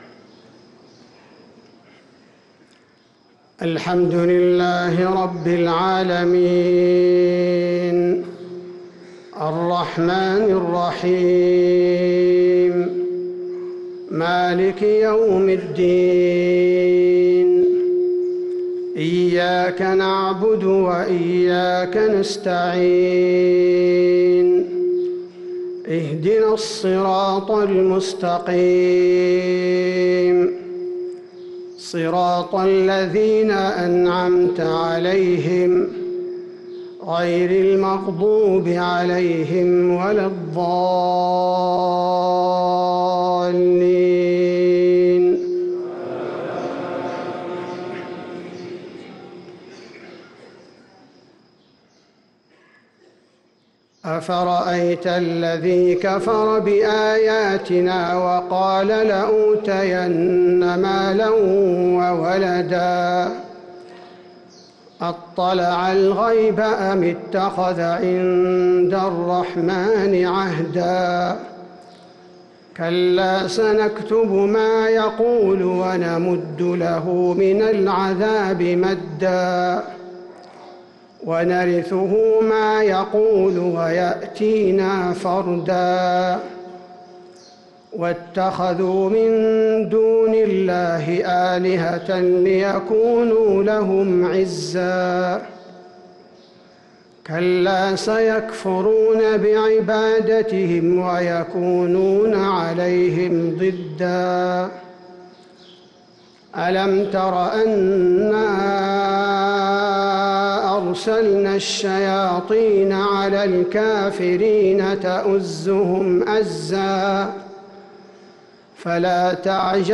صلاة الفجر للقارئ عبدالباري الثبيتي 28 رمضان 1444 هـ
تِلَاوَات الْحَرَمَيْن .